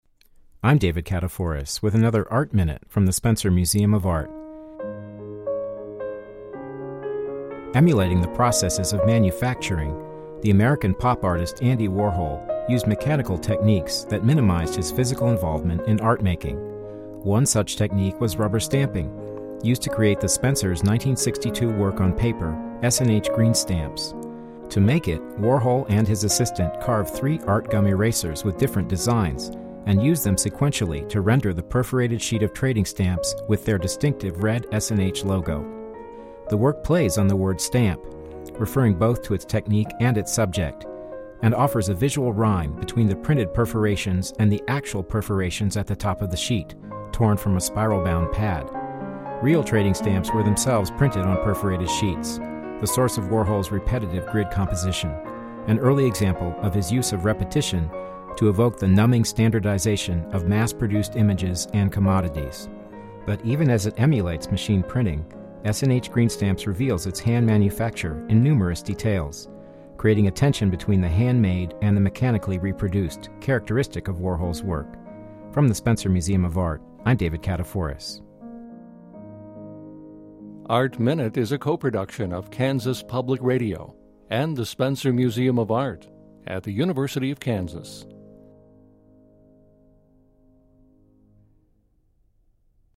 Didactic – Art Minute